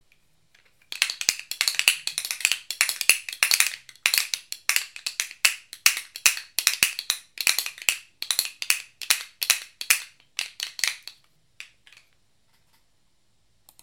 Pair of Painted Alligator Clappers from India.
Crocodile-clappers-1.mp3